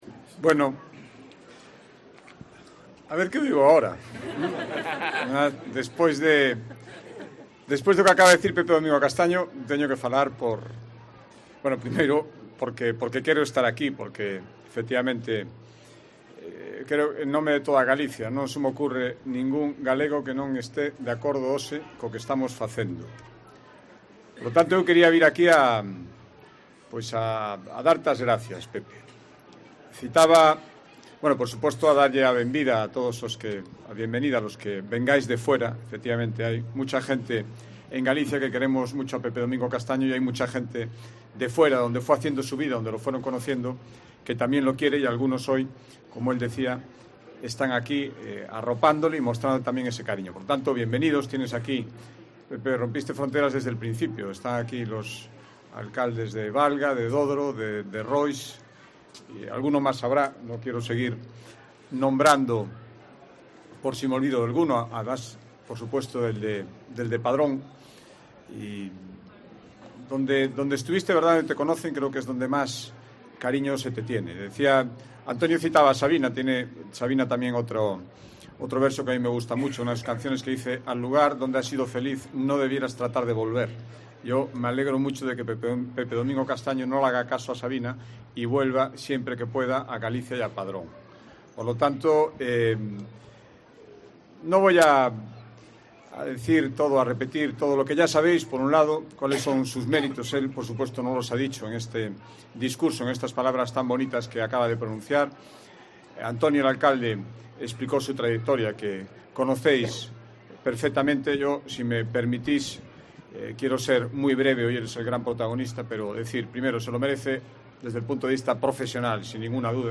Alfonso Rueda, Presidente de la Xunta destaca que a Pepe Domingo como un embajador de su tierra